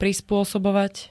prispôsobovať -buje -bujú -buj! -boval -bujúc -bujúci -bovaný -bovanie nedok.
Zvukové nahrávky niektorých slov